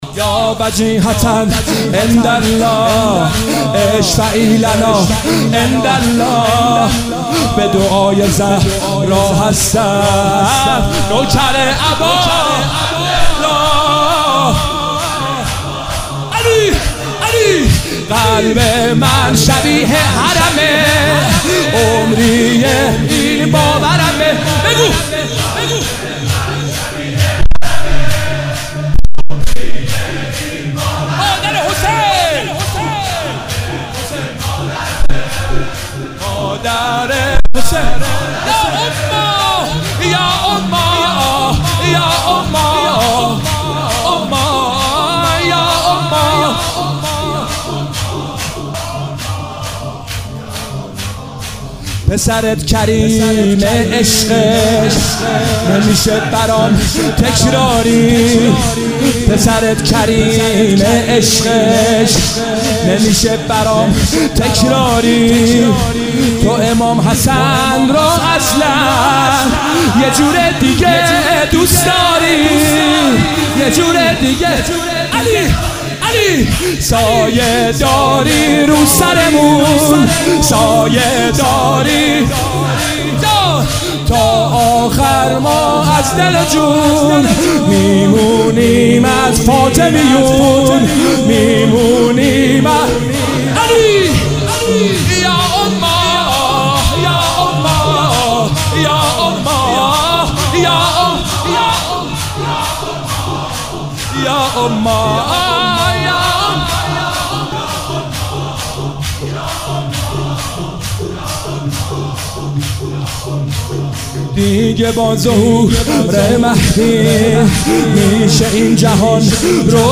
مداحی و نوحه
(شور)